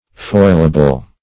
Foilable \Foil"a*ble\, a. Capable of being foiled.